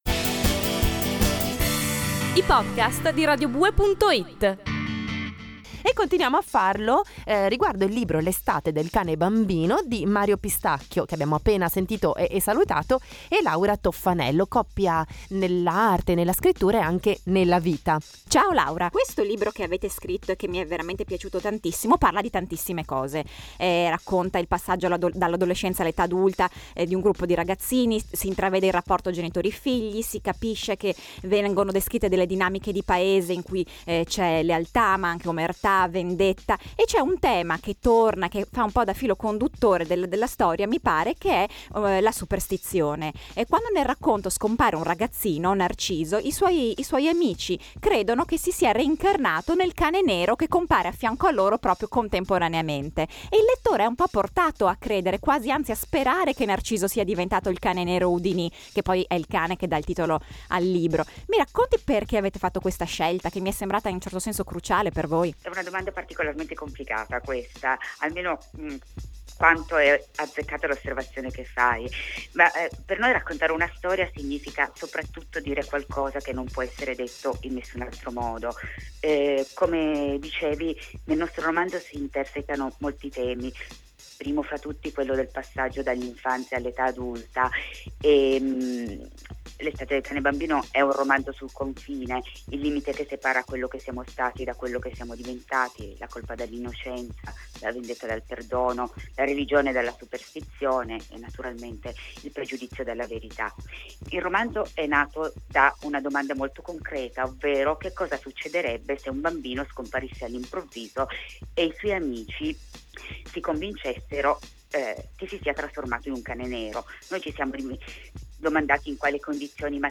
Interviste
Abbiamo avuto la fortuna di parlare del romanzo proprio con i suoi autori, che ci hanno raggiunte al telefono.